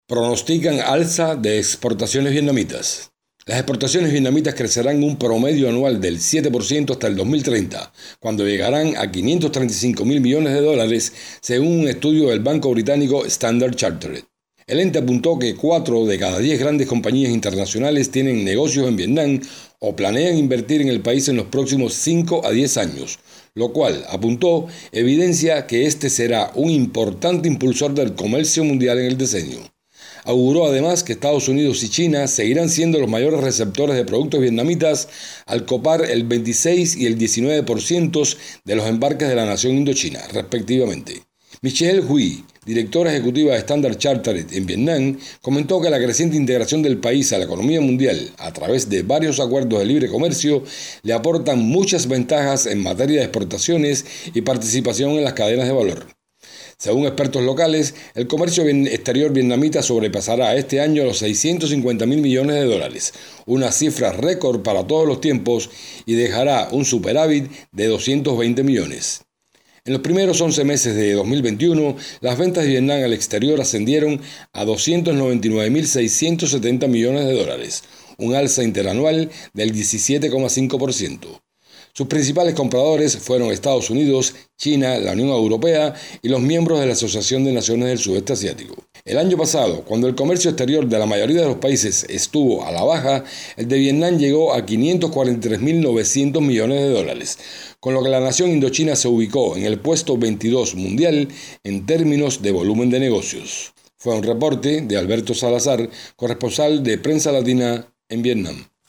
desde Hanoi